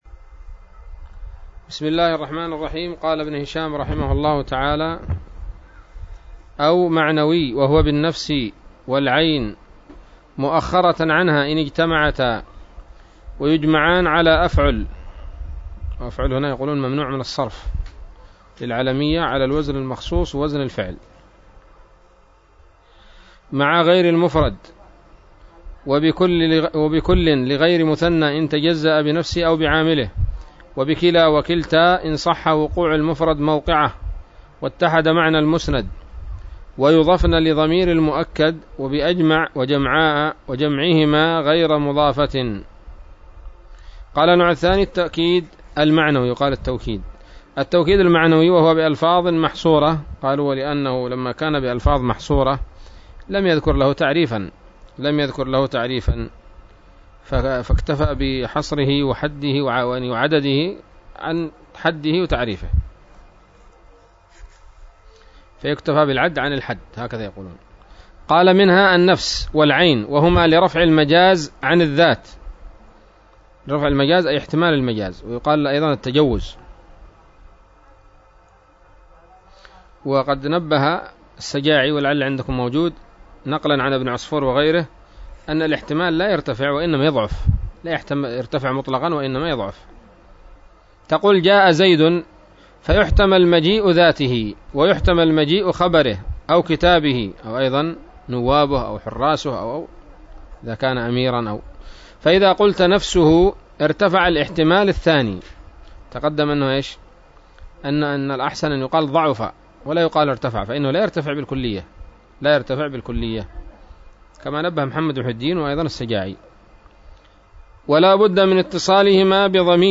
الدرس السادس عشر بعد المائة من شرح قطر الندى وبل الصدى